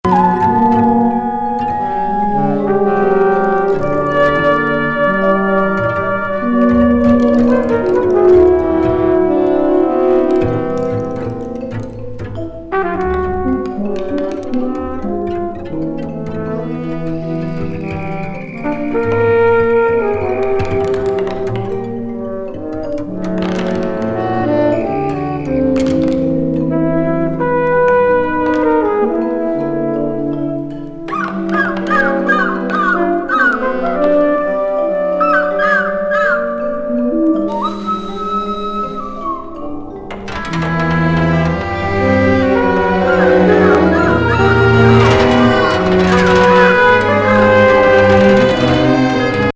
フレンチ・アンダーグラウンド!気鋭音楽家グループによる84年作。
チェンバー・ロック～舞台音楽などが混在するサウンド。